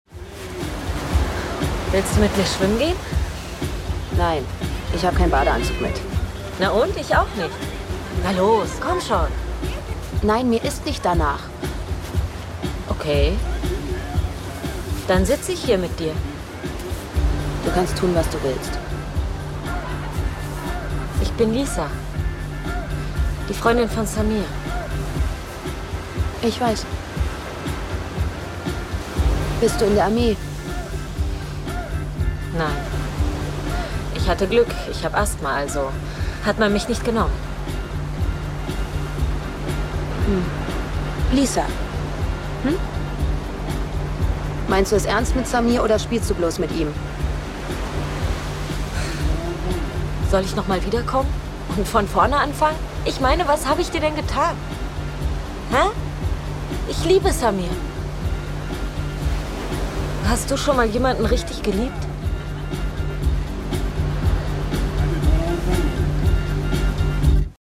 Synchronstimme Kinospielfilm Miral von Julian Schnabel